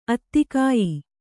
♪ attikāyi